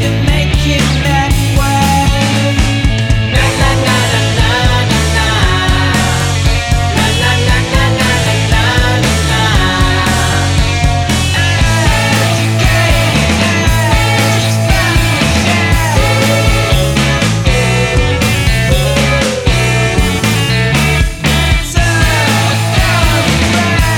no Backing Vocals Indie / Alternative 3:31 Buy £1.50